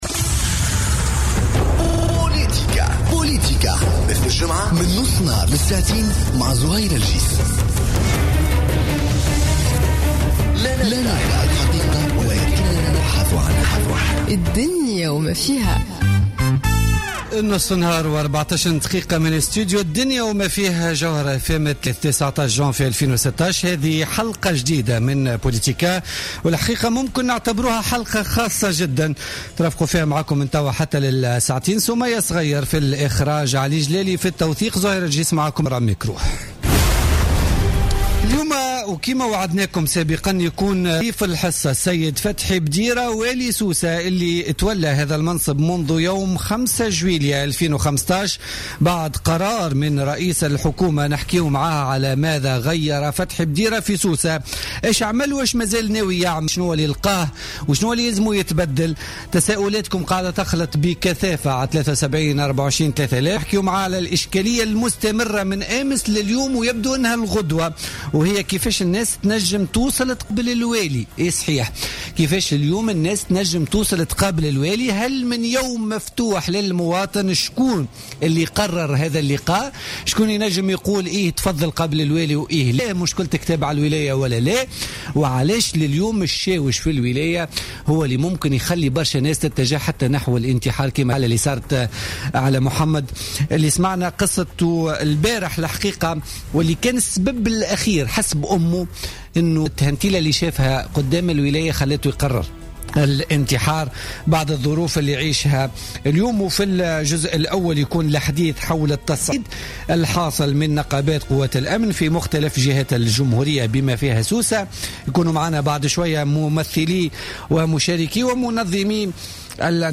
En direct sur Jawhara FM, le gouverneur de Sousse interagit avec les auditeurs